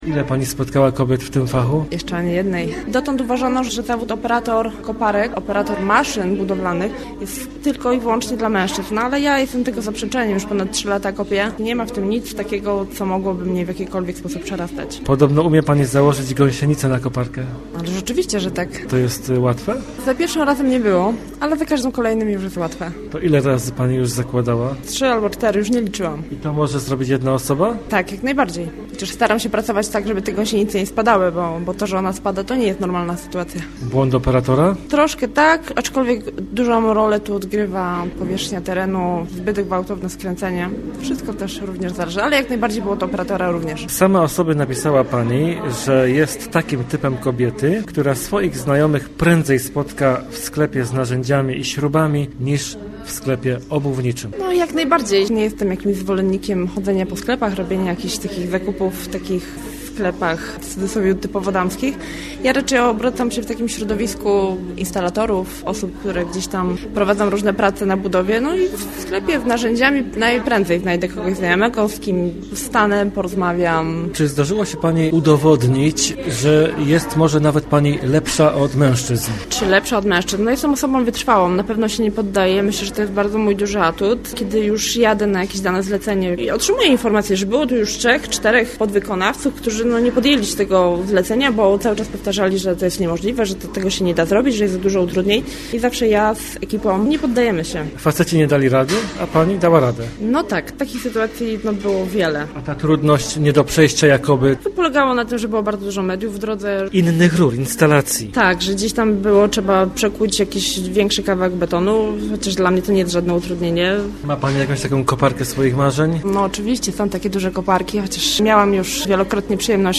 W scenerii świątecznej, nie na placu budowy